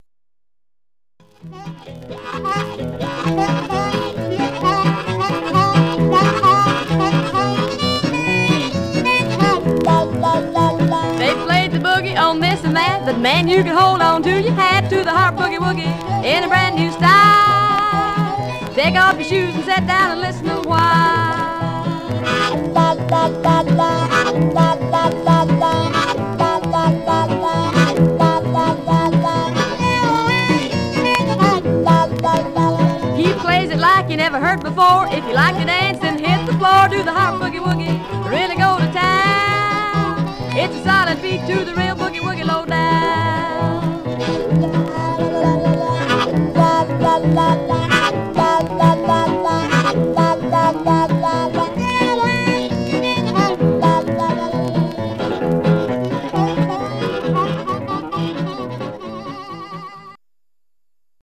Surface noise/wear
Mono
Country